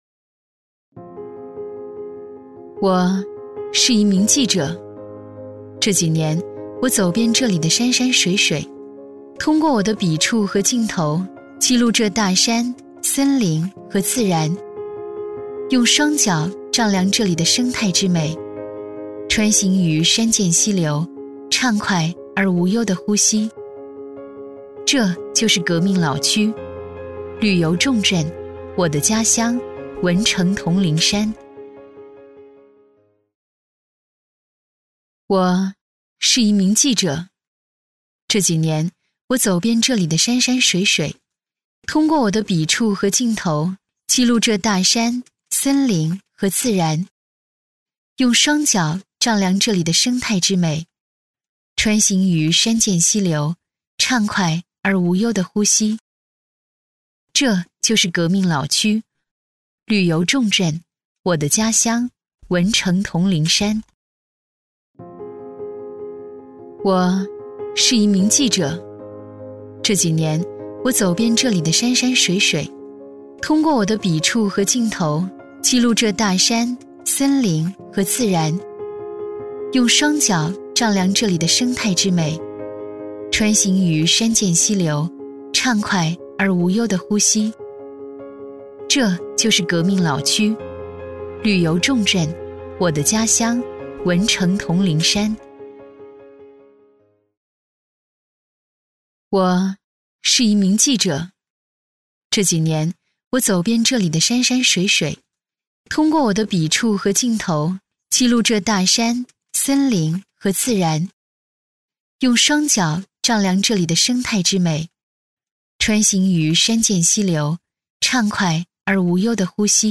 国语青年沉稳 、科技感 、积极向上 、亲切甜美 、女专题片 、宣传片 、80元/分钟女S112 国语 女声 专题片-城市改造-正式 沉稳|科技感|积极向上|亲切甜美 - 样音试听_配音价格_找配音 - voice666配音网